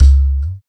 80 TLK DRM-R.wav